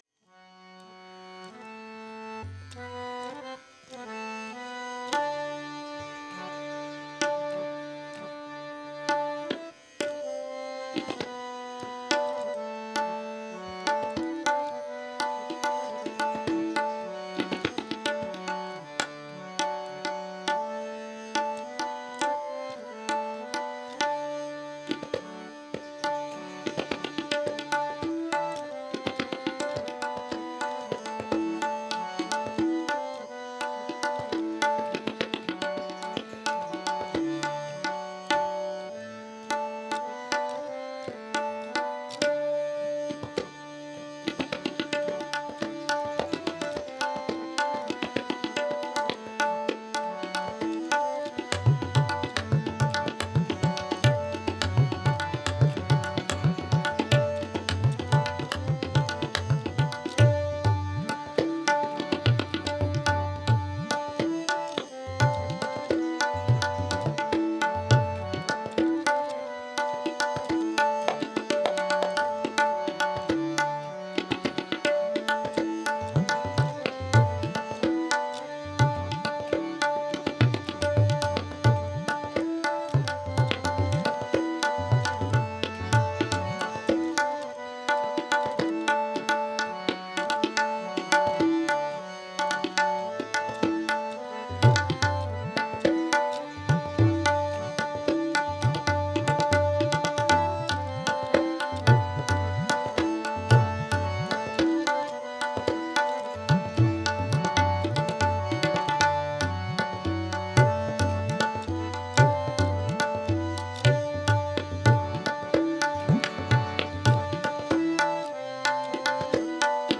The recordings below were mostly recorded at my residence.
The first batch of recordings below are from their last visit to Malaysia in 2015.
Tabla Solo